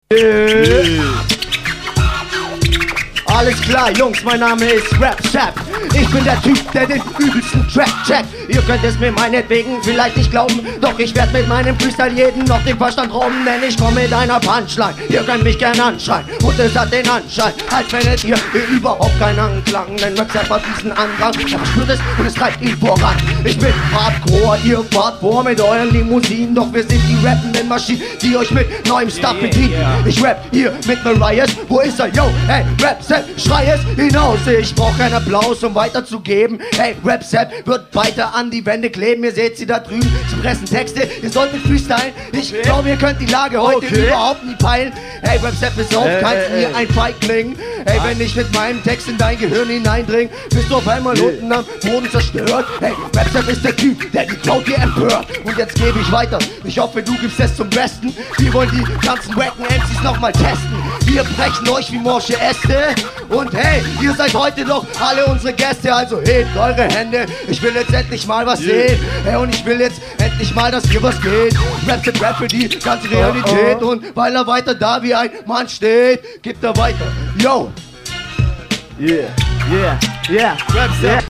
ich poste mal bei gelegenheit nen freestyle vom nem typen aus Böblingen (da komm ich her)...dann weisste was geht ;)